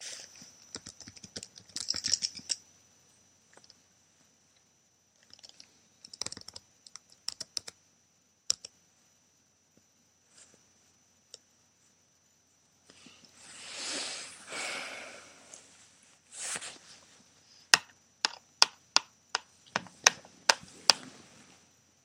Field Recording Number Eleven
Location: Tabor House, East Meadow
Sounds heard: Typing, clanking, breathing, tapping my fork on my computer.